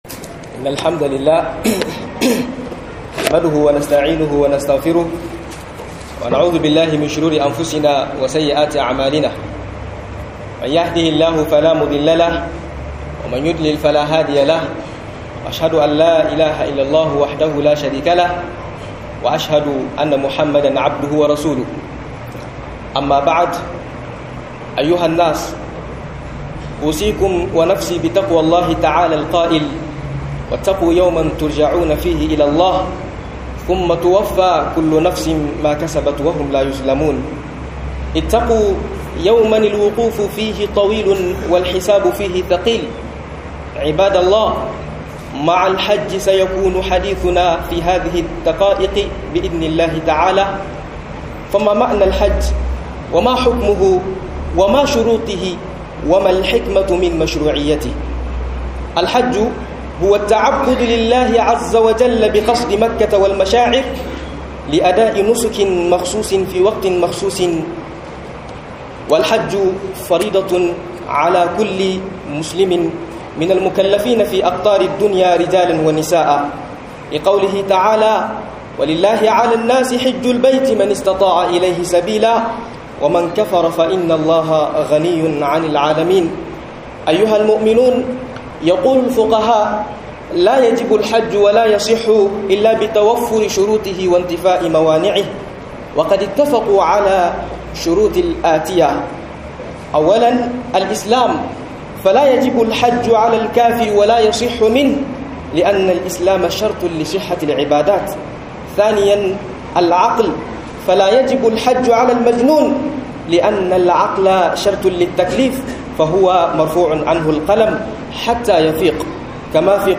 Aykin Hajji Manufarasa da Fa'idarsa - MUHADARA